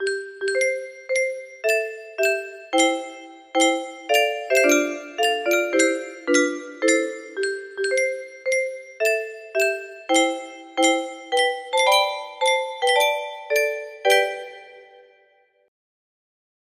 Die Stem 104 notes - G major music box melody